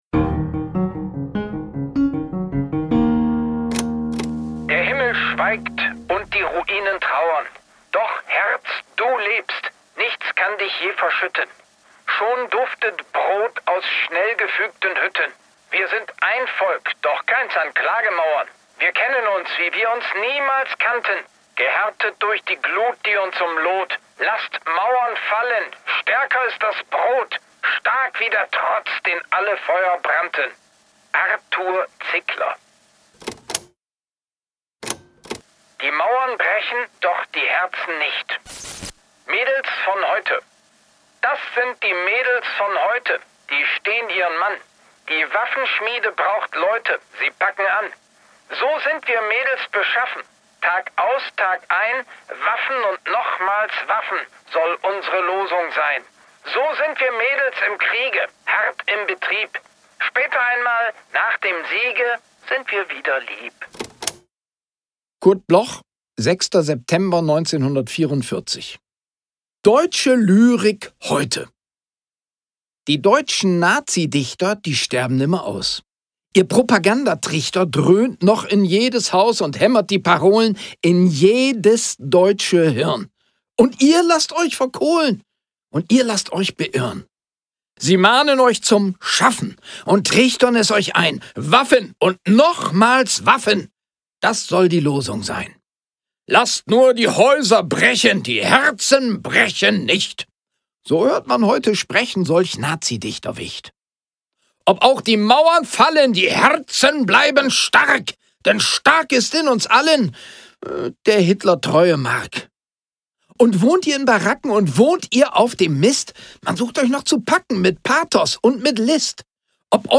performed by Wigald Boning
Wigald_Boning-Deutsche-Lyrik-heute_mit-Musik.m4a